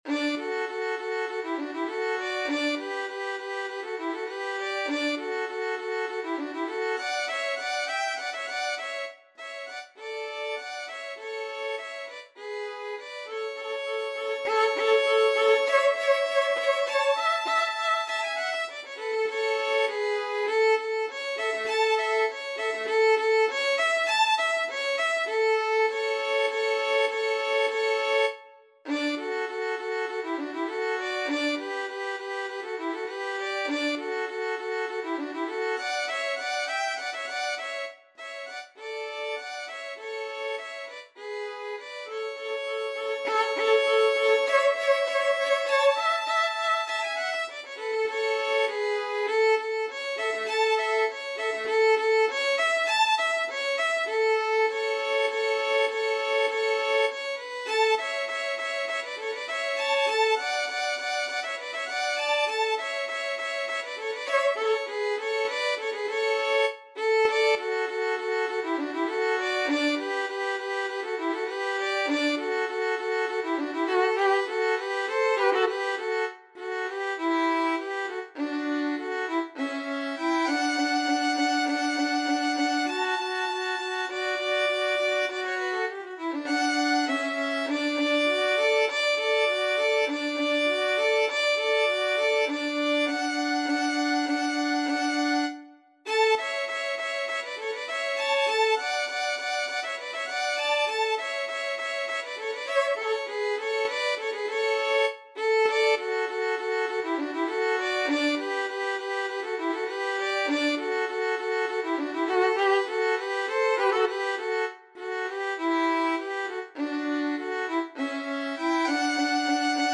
~ = 100 Allegretto
D major (Sounding Pitch) (View more D major Music for Violin Duet )
2/4 (View more 2/4 Music)
Violin Duet  (View more Intermediate Violin Duet Music)
Classical (View more Classical Violin Duet Music)